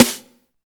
29 SNARE.wav